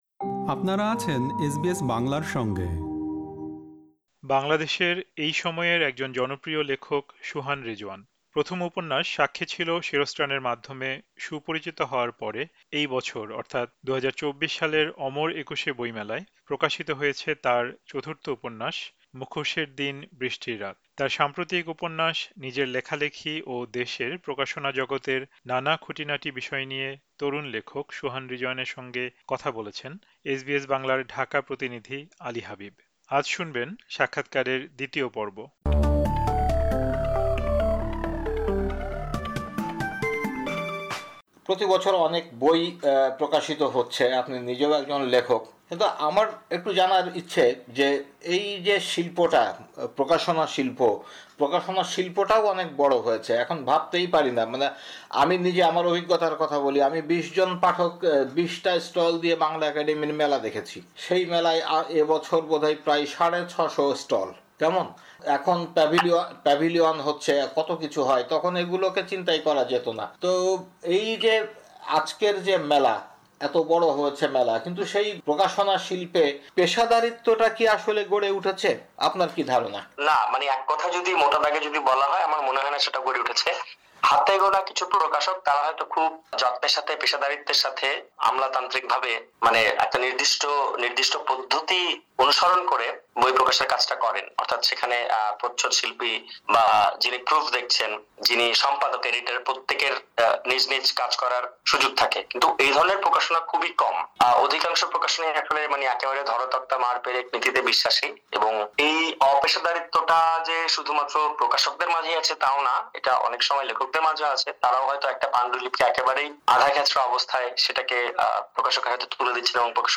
এখানে থাকছে সাক্ষাৎকারের শেষ পর্ব।